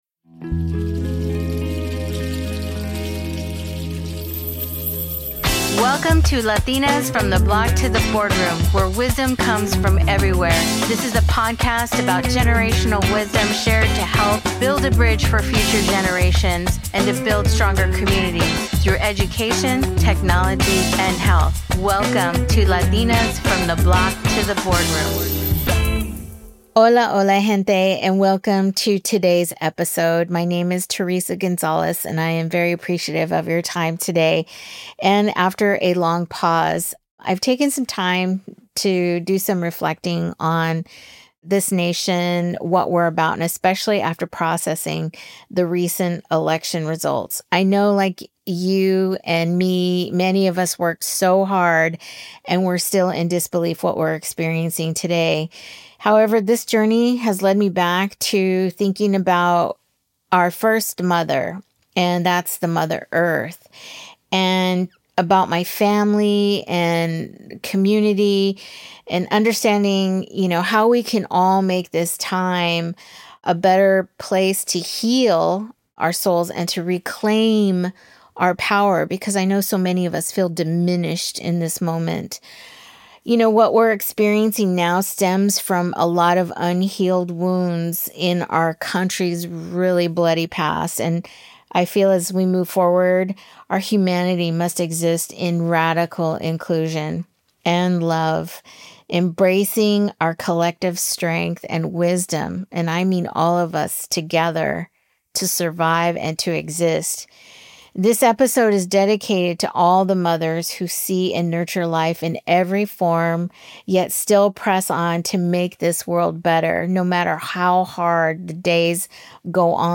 Conversations